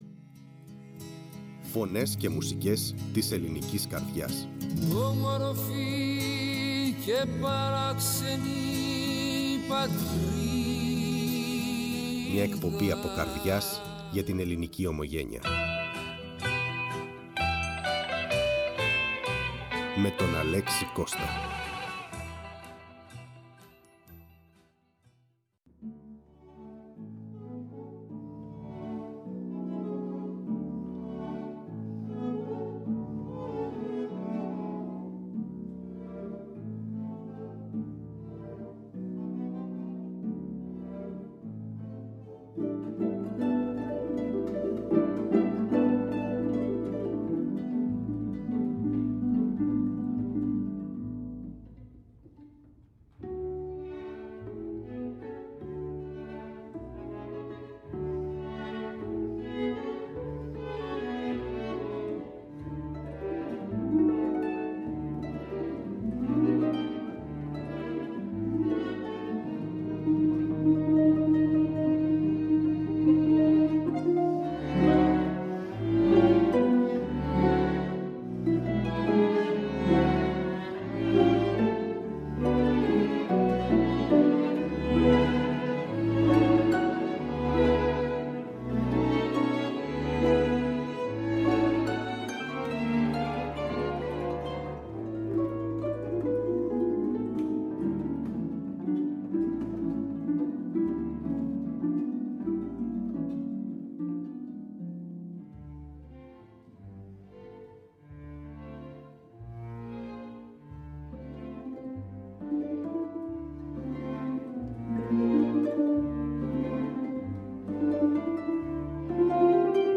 Η αρπίστρια
Αρπα